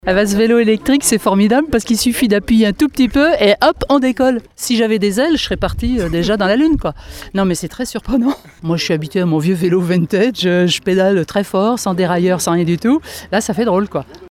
Et le service a déjà ses adeptes. Ecoutez cette nouvelle utilisatrice :